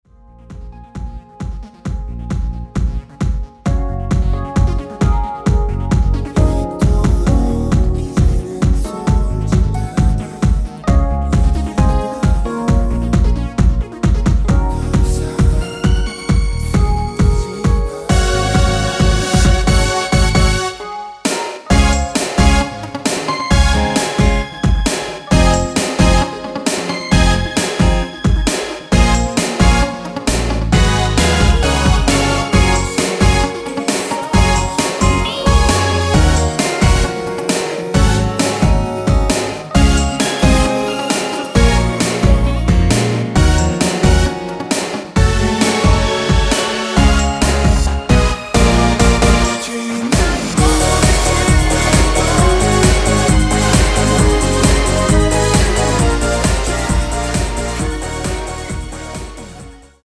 원키에서(-1)내린 코러스 포함된 MR입니다.
Db
앞부분30초, 뒷부분30초씩 편집해서 올려 드리고 있습니다.
중간에 음이 끈어지고 다시 나오는 이유는